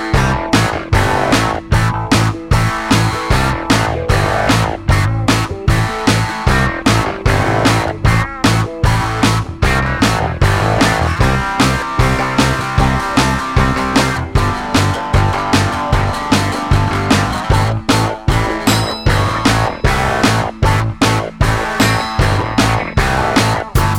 Minus Lead Guitar Pop (1970s) 3:03 Buy £1.50